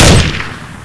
m4a1_unsil-1.wav